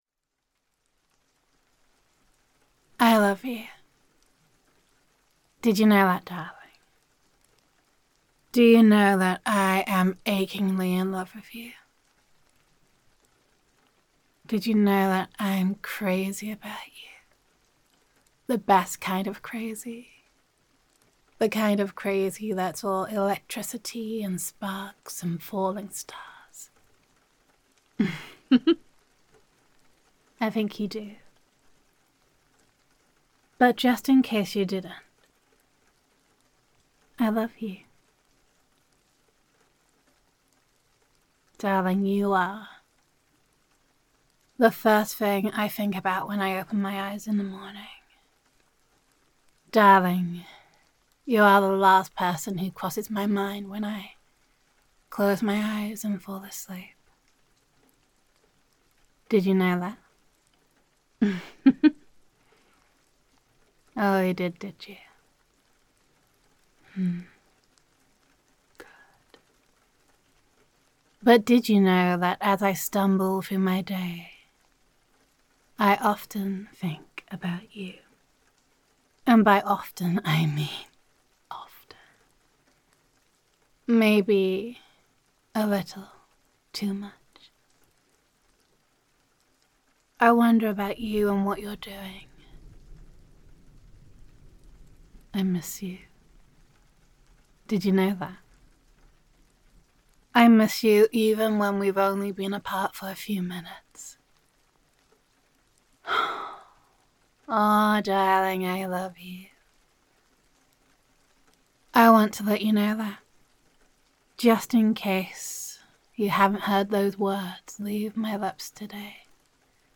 PATREON EXCLUSIVE AUDIO – [F4A] A Reminder That You Matter to Me [I Love You][I Adore You][I Am Here for You][Girlfriend Roleplay][Comfort][Reassurance][Gender Neutral][I Am Right Here and I Am Not Going Anywhere]